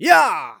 xys发力5.wav 0:00.00 0:00.60 xys发力5.wav WAV · 52 KB · 單聲道 (1ch) 下载文件 本站所有音效均采用 CC0 授权 ，可免费用于商业与个人项目，无需署名。
人声采集素材